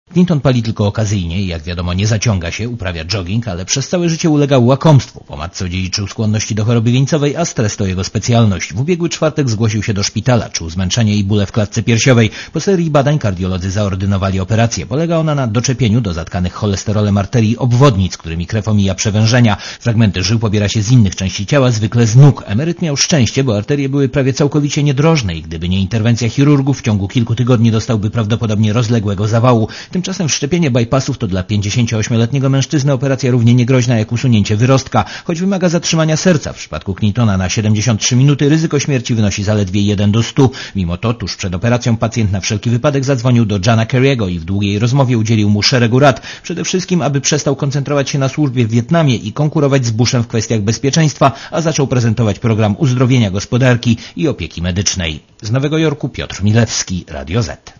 Korespondencja z USA